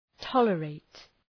Προφορά
{‘tɒlə,reıt}